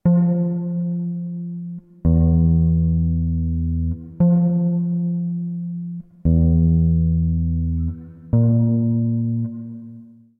guitar and other sounds
saxophone